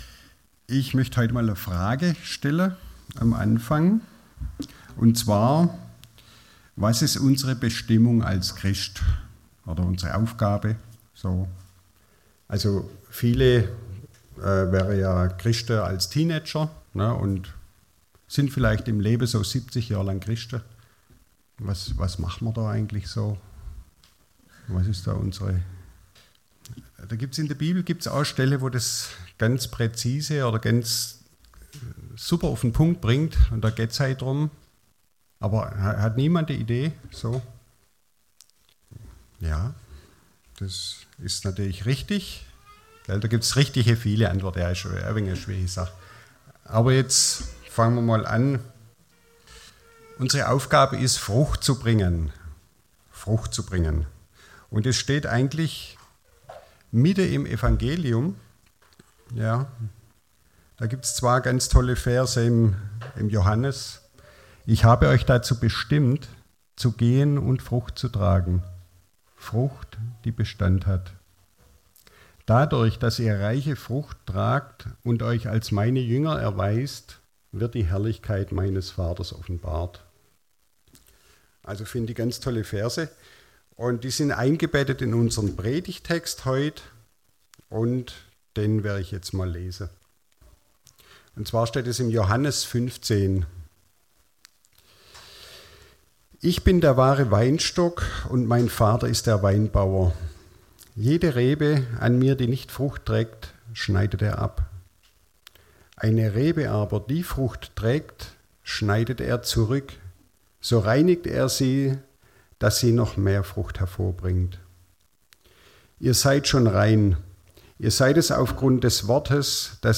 Predigt vom 25.